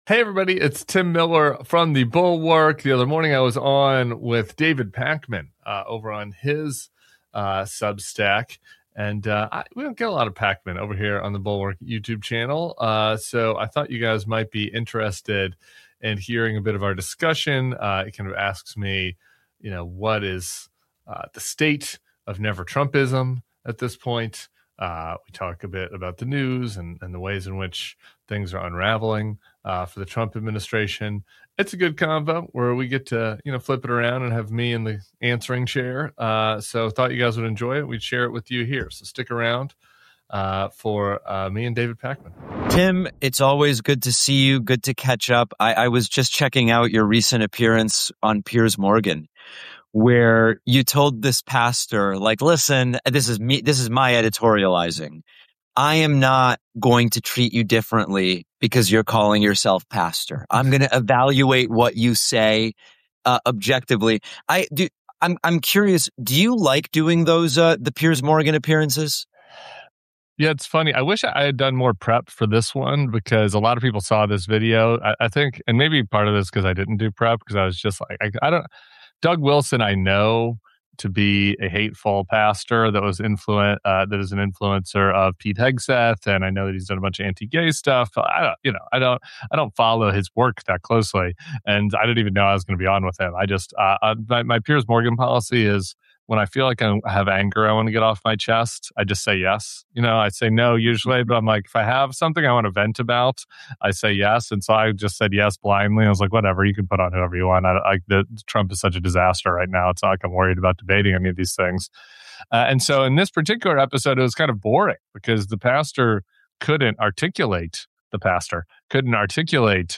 Tim Miller joins David Pakman for a wide-ranging conversation on Trump’s unraveling, the “MAGA betrayal” moment among anti-war influencers, and what comes next for the right. They discuss why some of Trump’s loudest supporters feel misled, who actually drives conservative media, and how Never Trump has evolved into something broader. Plus: how to talk to voters who are starting to reconsider—and what a post-Trump GOP might look like.